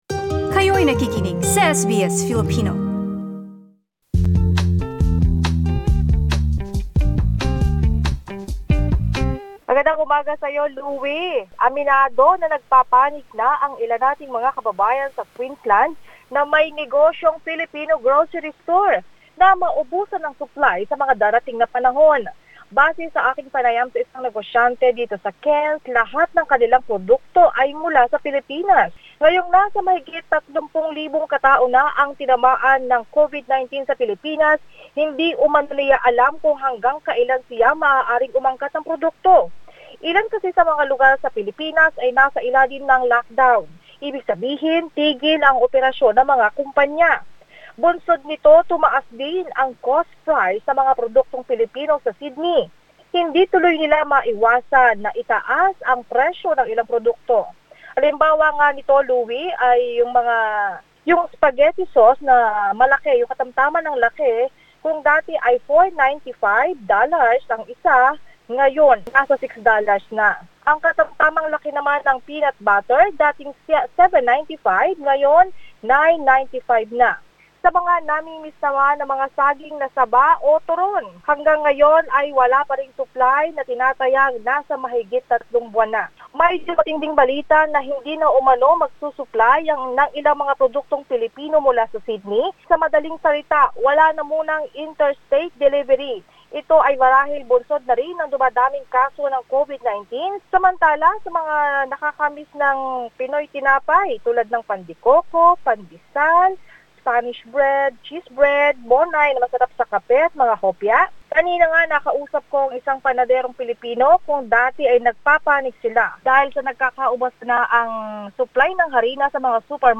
Sa aming panayam sa isang negosyante sa Cairns, halos lahat ng kaniyang produkto ay mula sa Pilipinas.